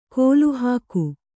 ♪ kōluhāku